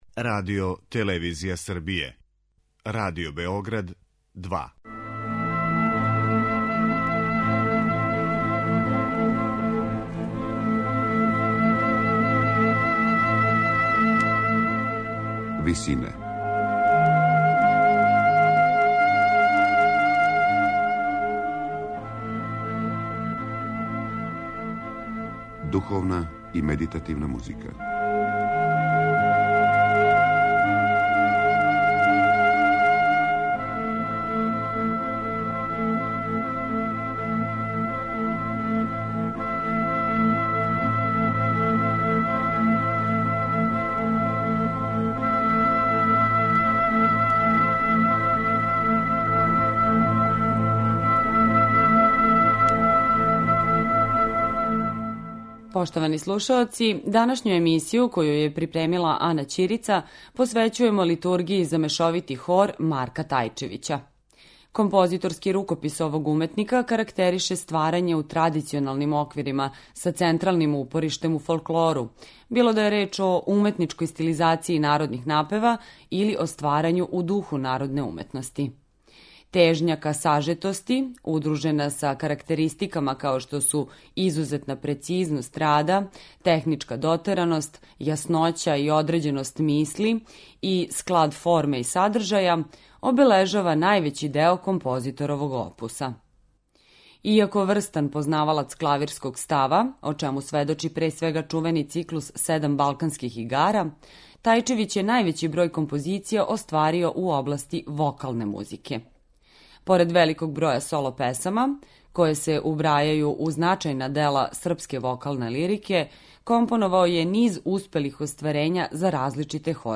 Слушаћете снимак који је остварен у мају 1985. године